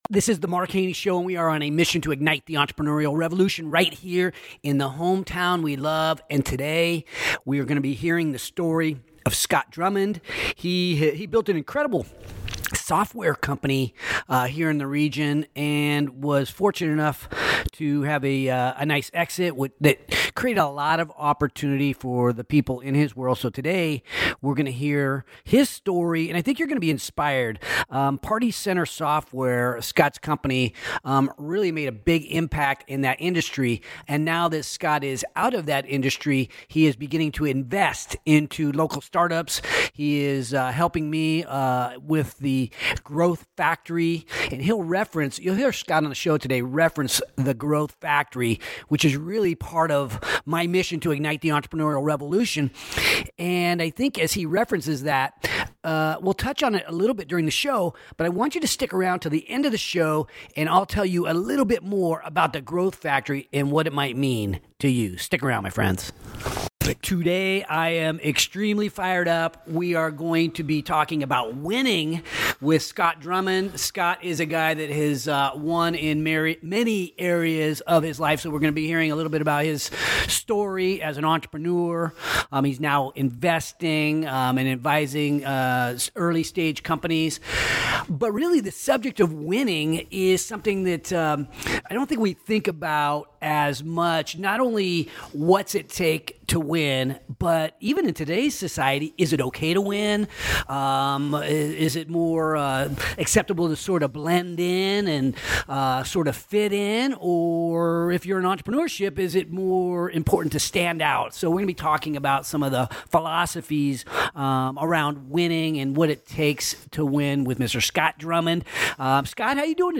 engaging conversation